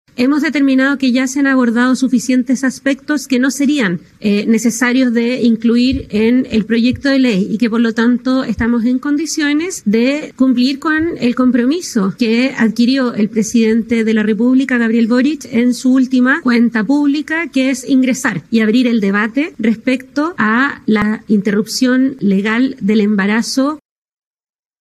De hecho, la ministra de la Mujer, Antonia Orellana, sostuvo que, pese a que el reglamento respecto al aborto tres causales sigue en Contraloría, de igual manera se ingresará esta semana el proyecto de aborto.